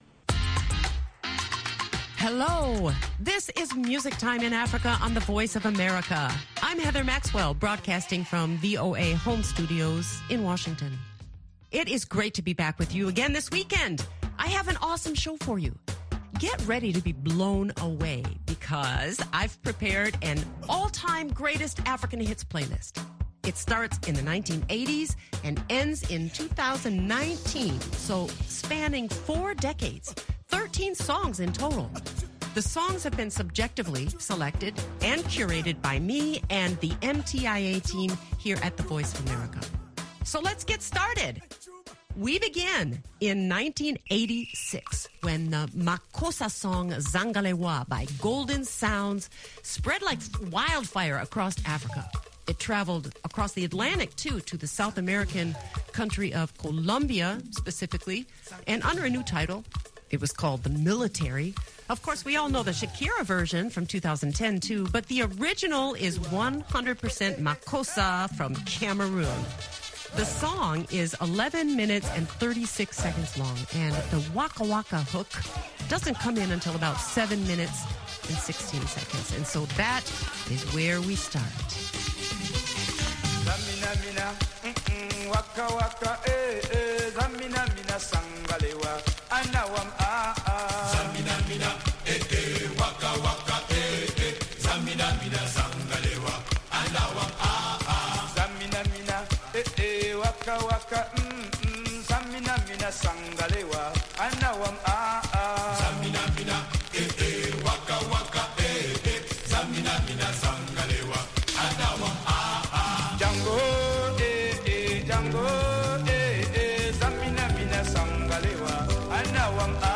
Music Time in Africa is VOA’s longest running English language program. Since 1965 this award-winning program has featured pan African music that spans all genres and generations.